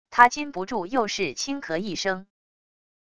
他禁不住又是轻咳一声wav音频